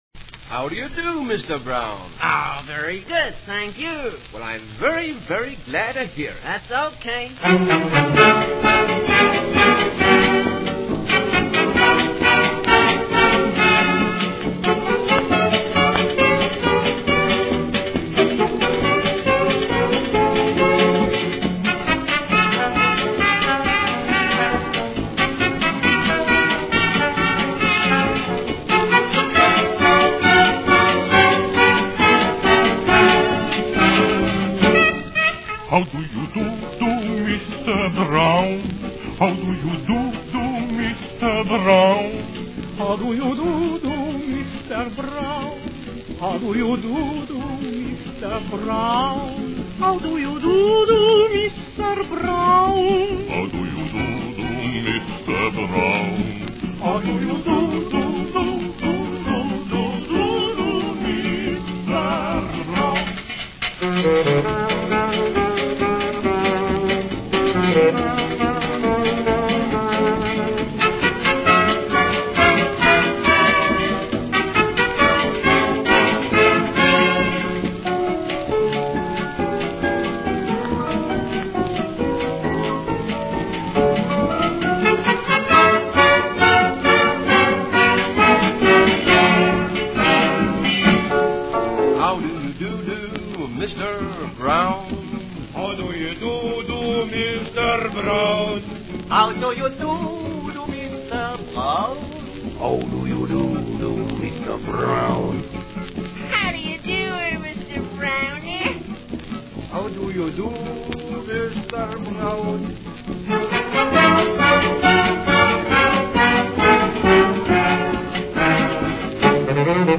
Вот эта милая песенка-фокстрот 1932 года.